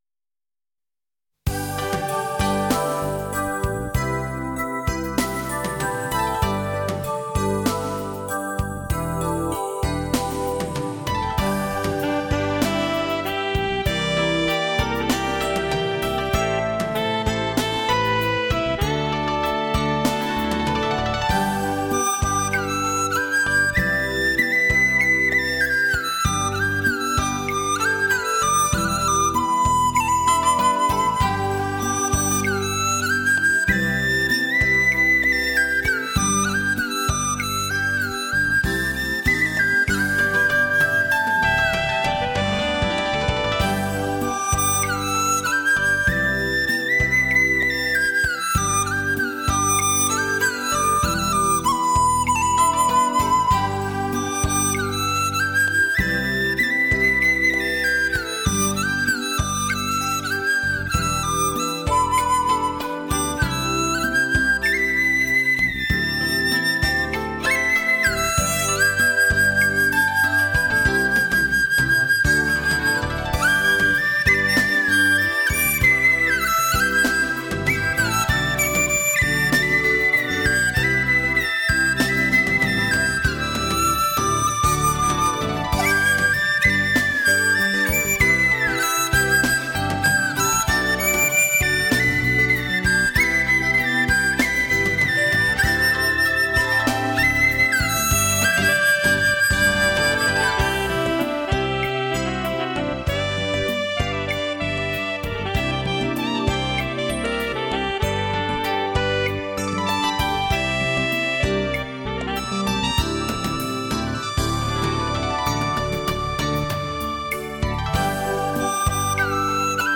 悠扬的竹笛声化为阵阵微熏的清风，犹如仙乐从天飘来，将人引入那限辽阔的神韵境界，曲不醉人人自醉。清新的田园，悠扬的笛声！